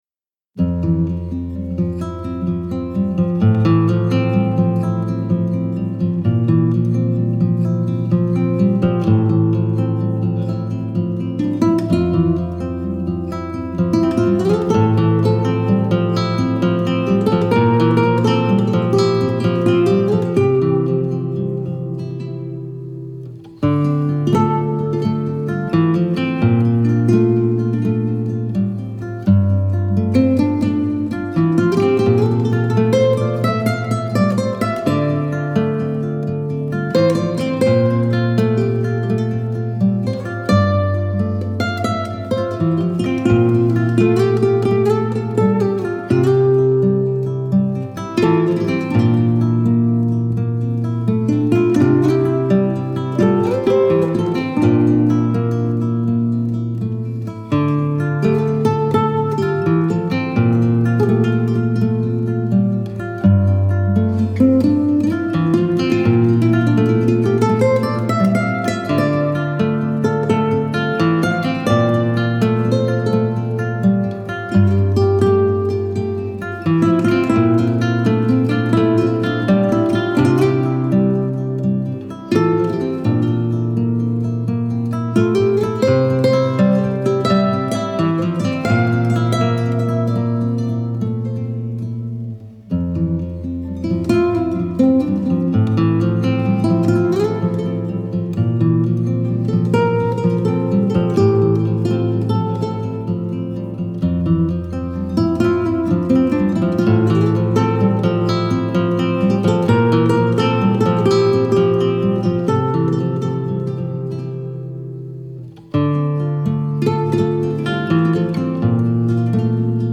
آرامش بخش , عاشقانه , عصر جدید , گیتار , موسیقی بی کلام